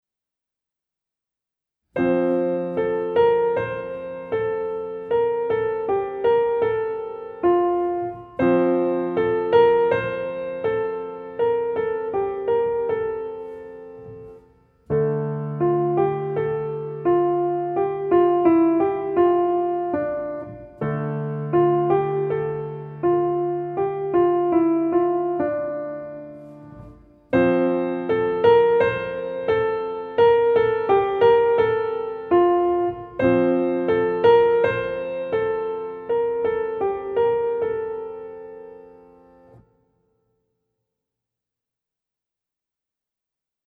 Gattung: Klavier
Besetzung: Instrumentalnoten für Klavier
stilistisch eher traditionell und volksliedhaft